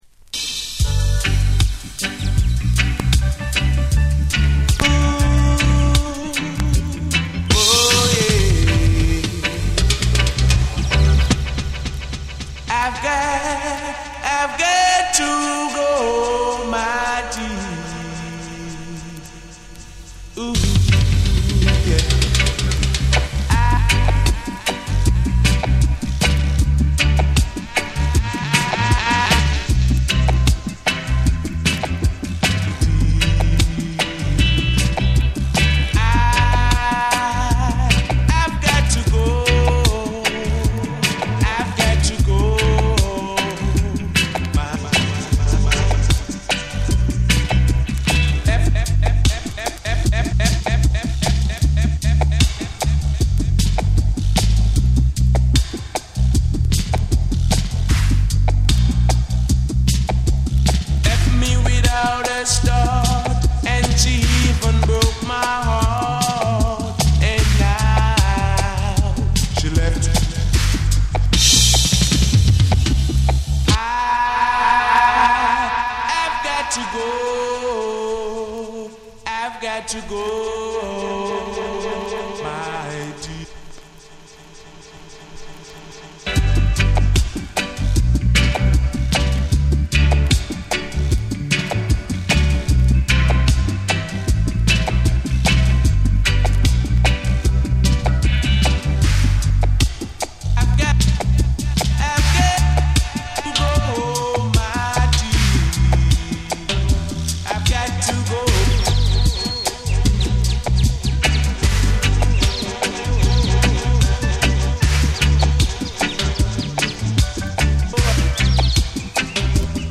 ※B1に針飛び箇所あり。
REGGAE & DUB